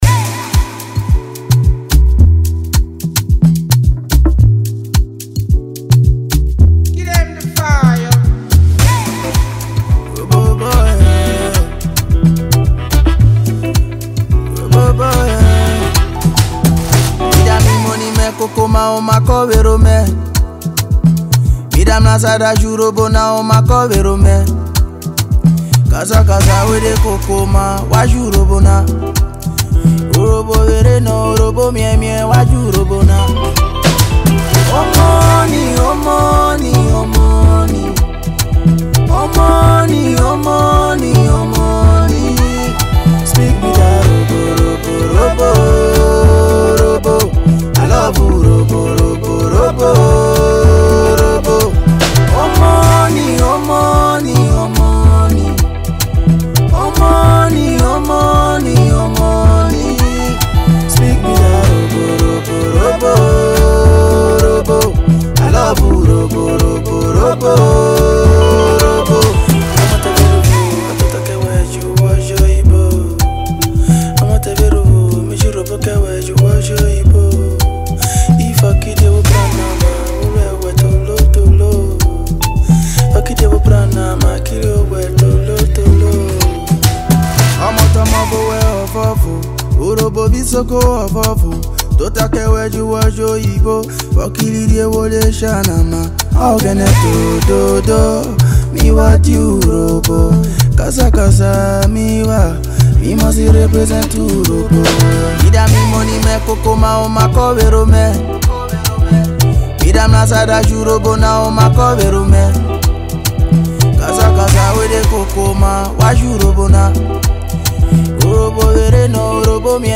Nigerian Afro Pop/ AfroBeat artiste